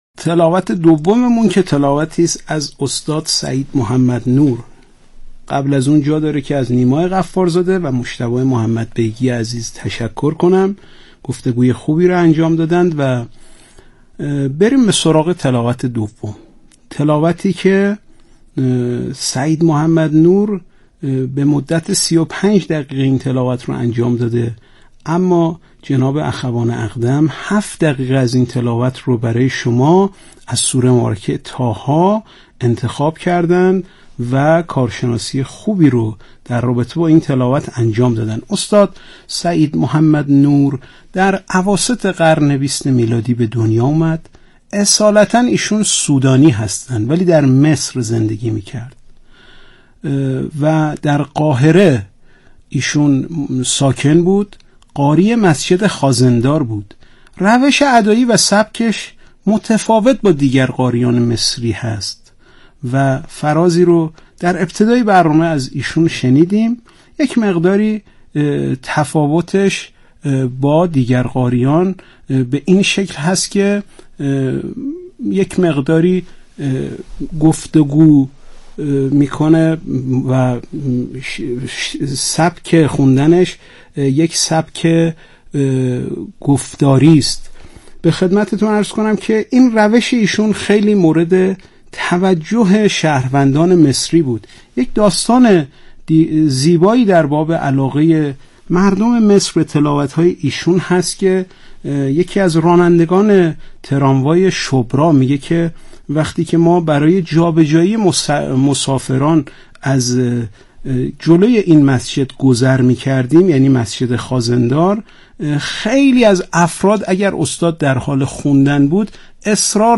یادآوری می‌شود این تحلیل در برنامه «اکسیر» از شبکه رادیویی قرآن پخش شد.